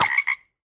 1.21.5 / assets / minecraft / sounds / mob / frog / hurt5.ogg
hurt5.ogg